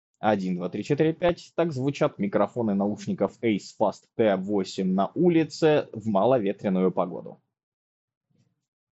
Заявленное активное шумоподавление, которое должно работать при телефонных разговорах, мало того, что работает и при записи с диктофона, так еще и неплохо срезает шум.
Я же скажу — звук не эталонный, но для телефонных разговоров наушники подходят целиком и полностью.
В шумных условиях:
ulica.mp3